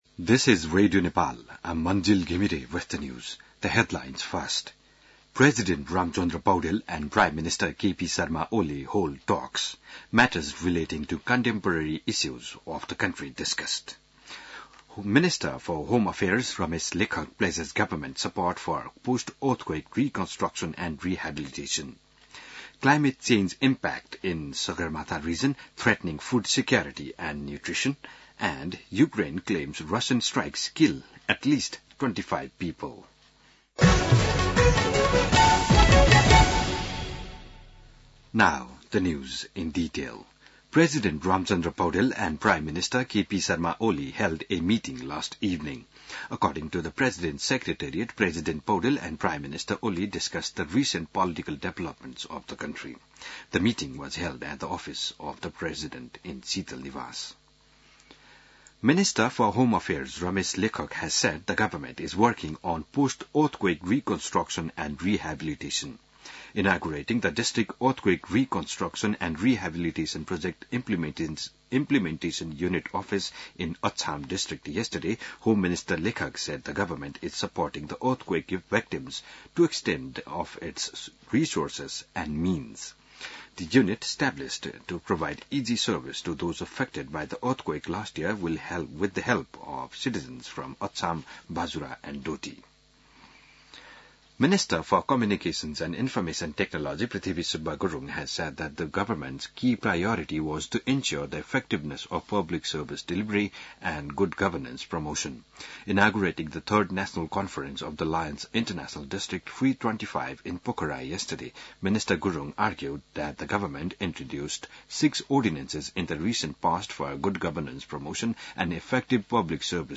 बिहान ८ बजेको अङ्ग्रेजी समाचार : २६ फागुन , २०८१